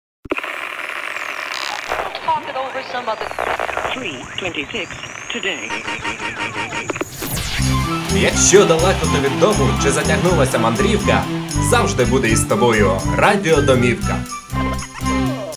Тракт: Студийный микрофон Marshall MXL990, аудио интерфейс ALESIS IO2 EXPRESS , электро пианино-синтезатор Сasio CDP-200.